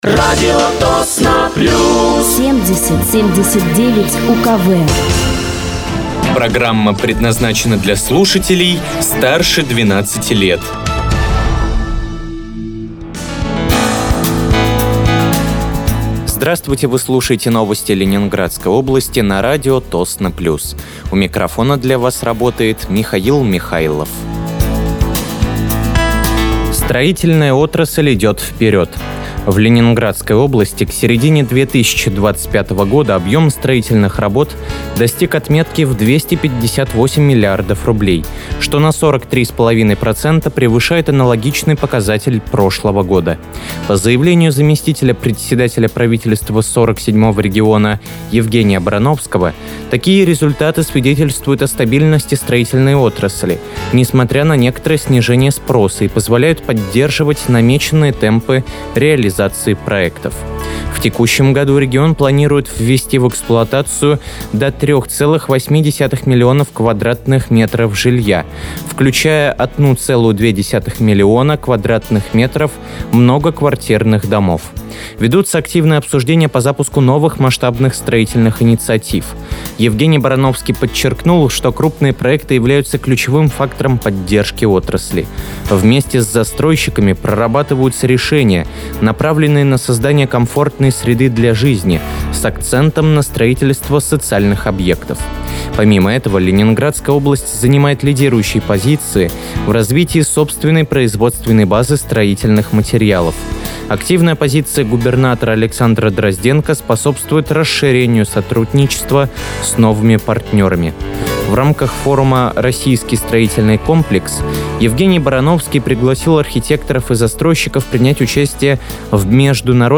Выпуск новостей Ленинградской области от 01.10.2025
Вы слушаете новости Ленинградской области от 01.10.2025 на радиоканале «Радио Тосно плюс».